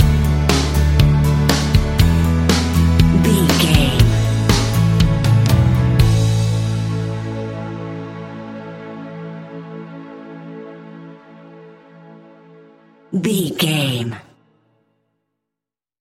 Uplifting
Ionian/Major
fun
energetic
instrumentals
guitars
bass
drums
organ